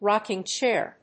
アクセント・音節róck・ing chàir